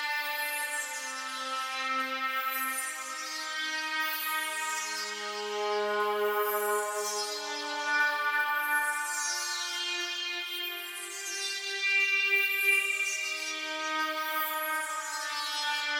环境现实垫子2
Tag: 120 bpm Ambient Loops Pad Loops 2.69 MB wav Key : C